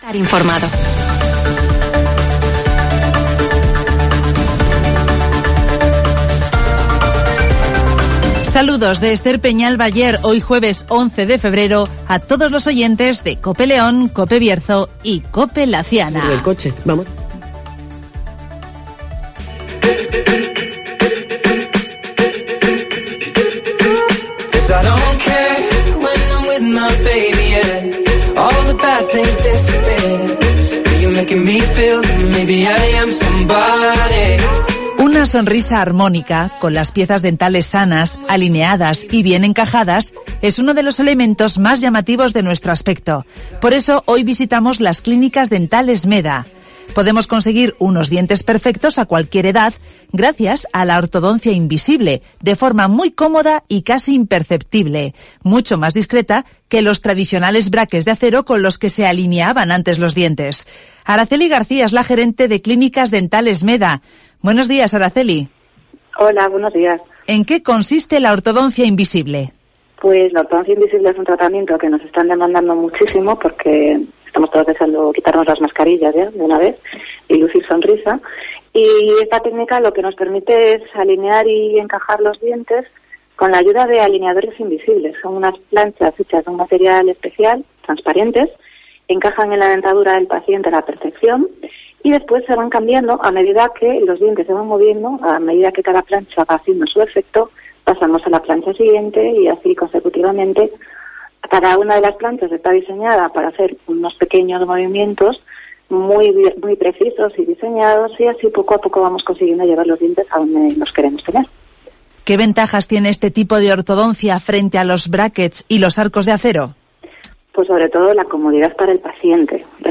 Luce una sonrisa armónica, con las piezas dentales sanas, alineadas y bien encajadas con las Clínicas Meda (Entrevista a...